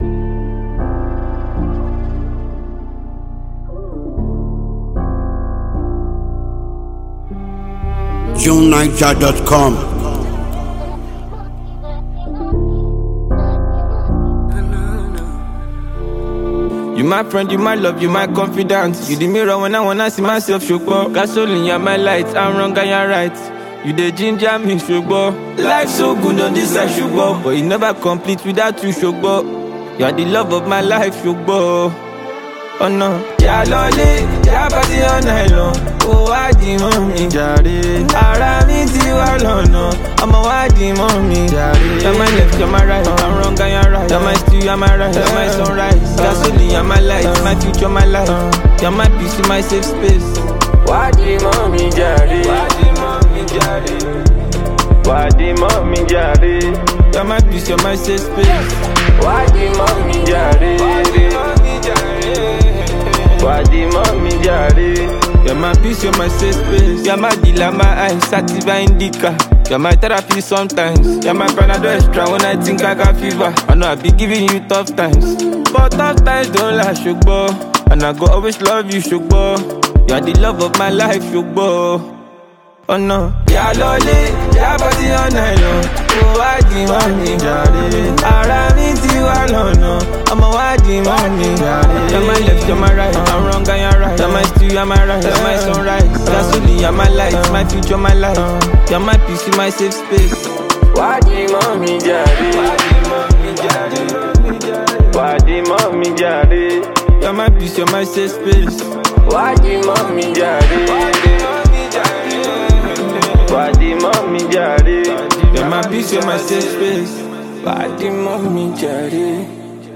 It’s simple to unwind to the song’s serene, reassuring vibe.
soft, sentimental music